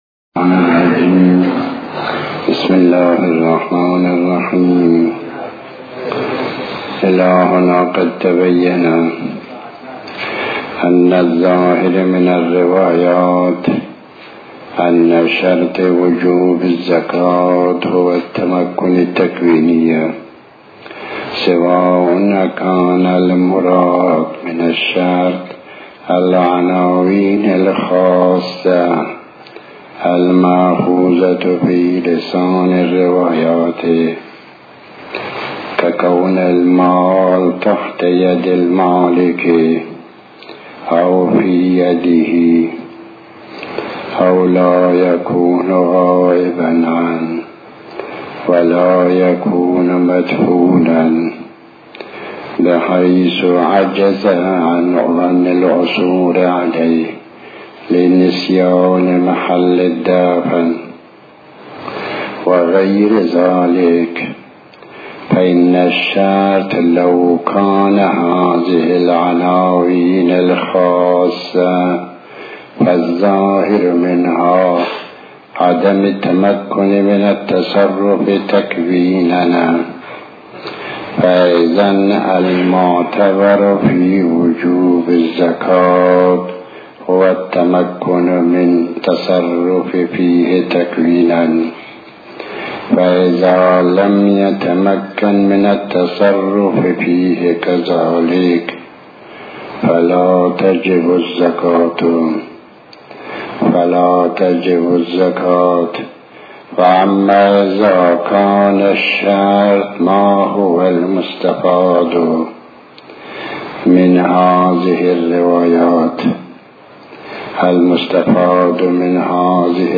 تحمیل آیةالله الشيخ محمداسحاق الفیاض بحث الفقه 38/01/30 بسم الله الرحمن الرحيم الموضوع:- خــتــام.